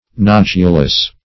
Meaning of nodulous. nodulous synonyms, pronunciation, spelling and more from Free Dictionary.
Search Result for " nodulous" : The Collaborative International Dictionary of English v.0.48: Nodulose \Nod"u*lose`\ (n[o^]d"[-u]*l[=o]s`), Nodulous \Nod"u*lous\ (n[o^]d"[-u]*l[u^]s), a. (Biol.)